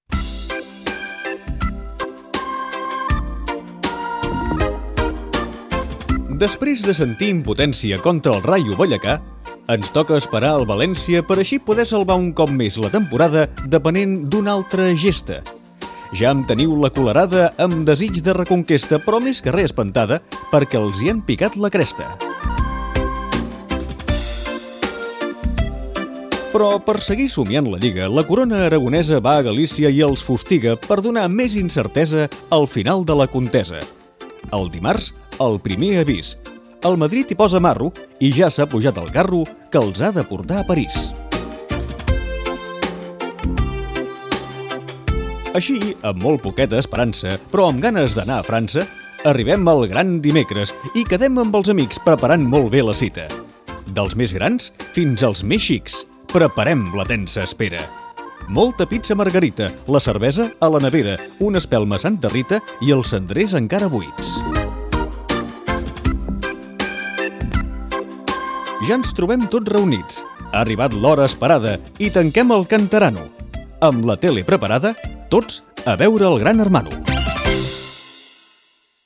Poema recollint l'actualitat esportiva del Futbol Club Barcelona
Esportiu
Programa presentat per Joan Maria Pou.